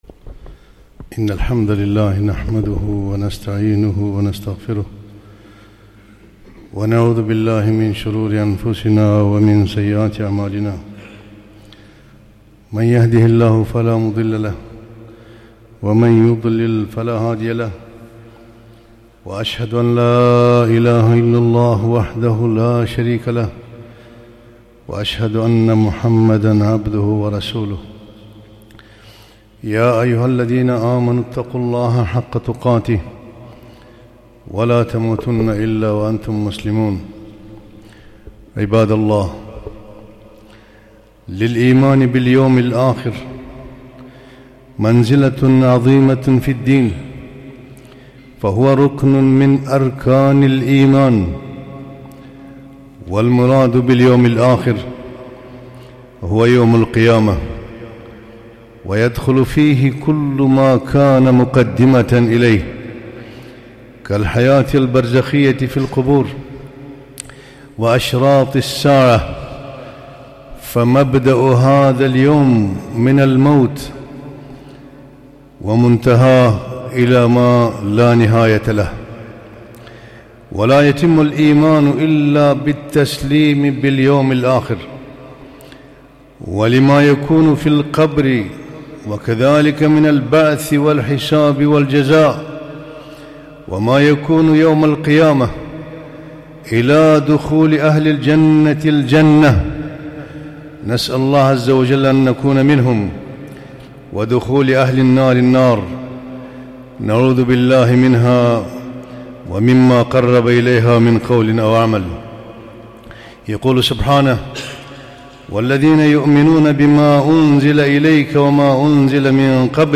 خطبة - الإيمان بالميزان يوم القيامة